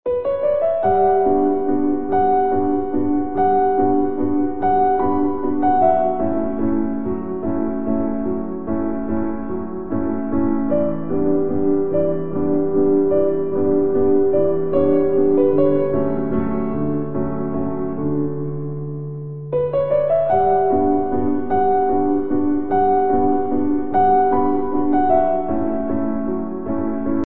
Like Lord Shiva, the creator and destroyer, who carries Rudra’s force within him, this semi-acoustic masterpiece channels the same essence of power and transformation. As the pinnacle of Mantra’s guitar craftsmanship, it resonates with depth, clarity, and transcendence in every note.